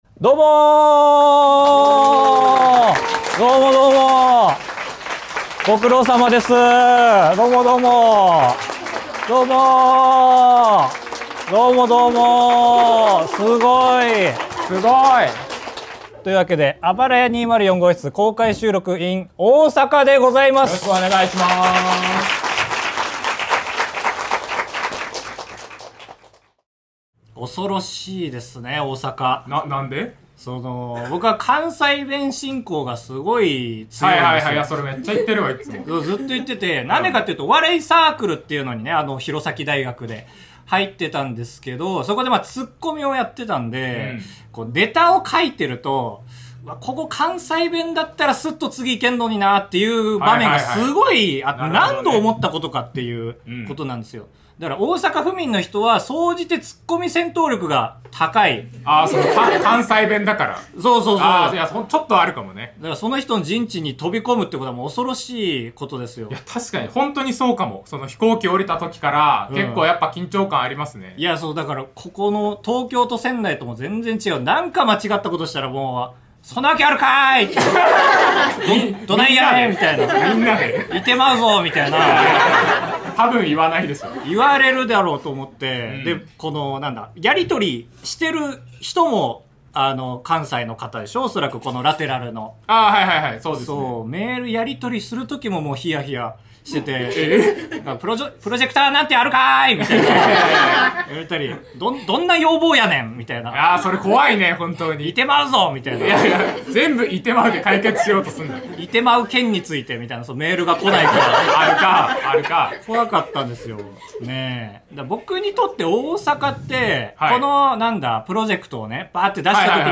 第245回「来たぞ大阪！公開収録３カ所目！西は怖ぇ！！」#496